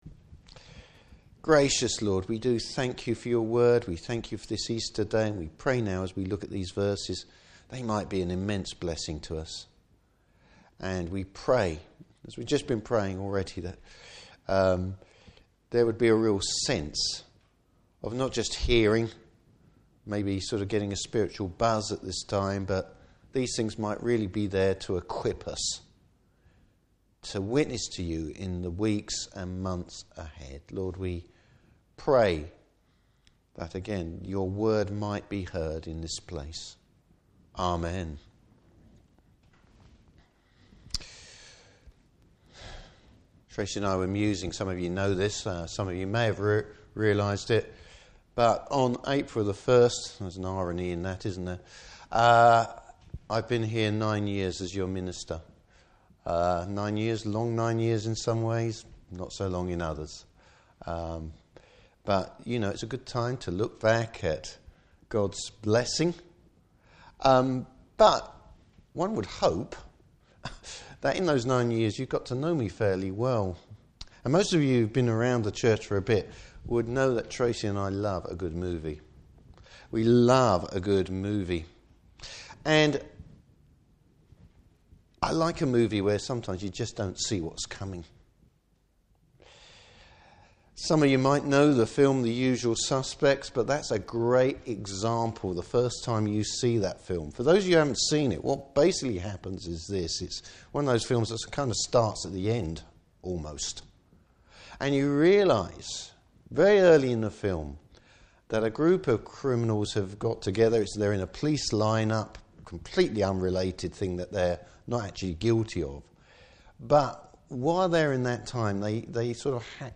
Service Type: Easter Day Morning Service.